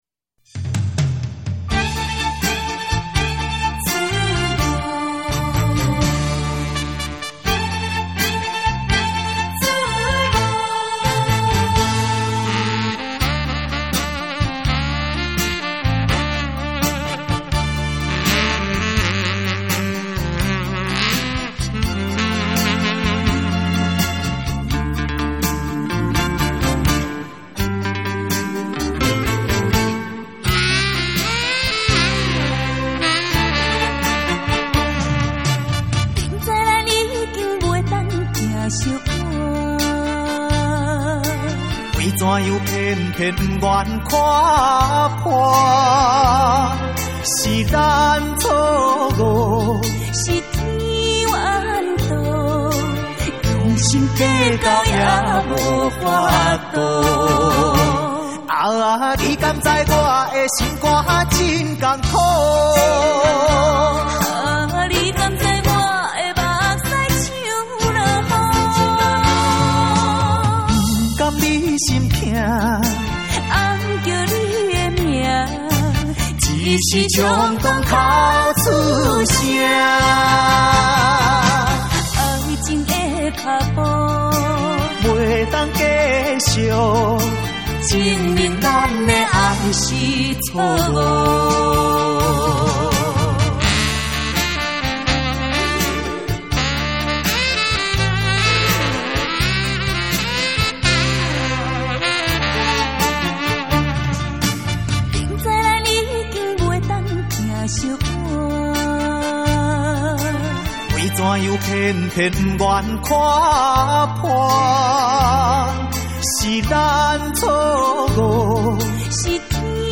试听为低音质